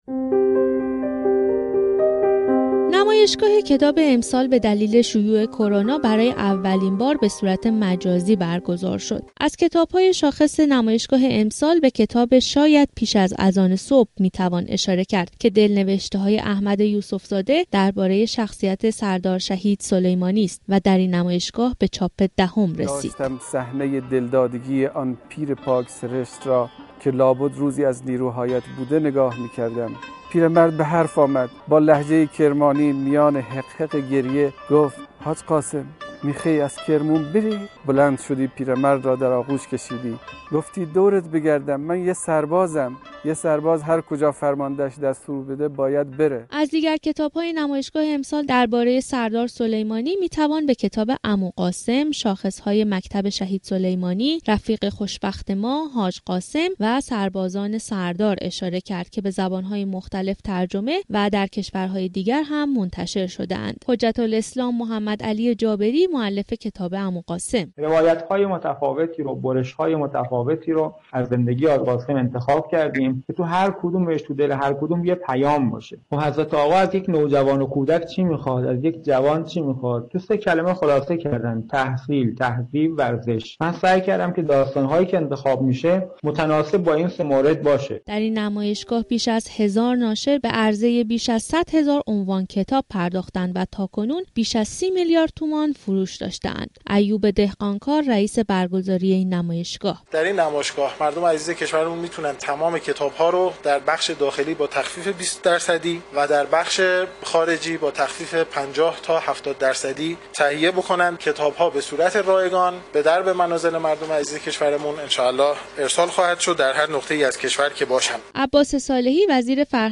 گزارش خبرنگار رادیو زیارت را بشنوید: